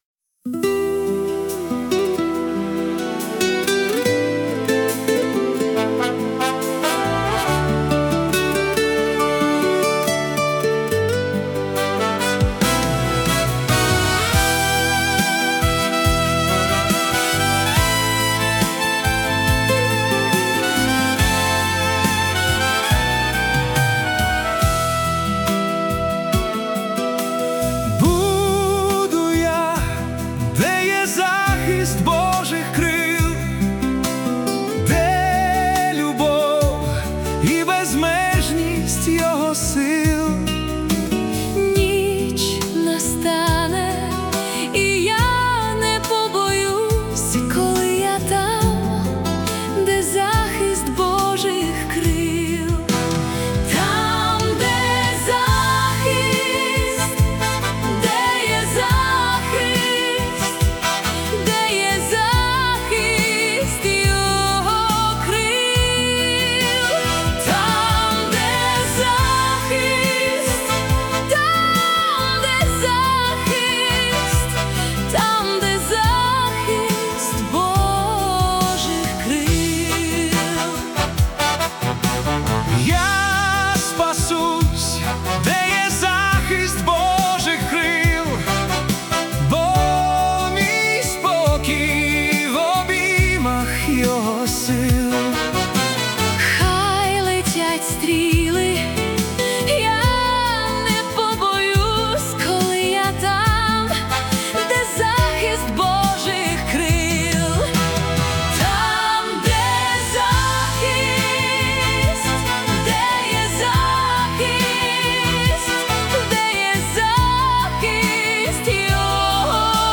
Духовна сповідь у ритмі класичної естради.
Класична естрада 90-х: Мелодія віри та надії 🎹
м'який чоловічий вокал з ніжним жіночим бек-вокалом
Божий захист, світло, небесні крила, духовна пісня естрада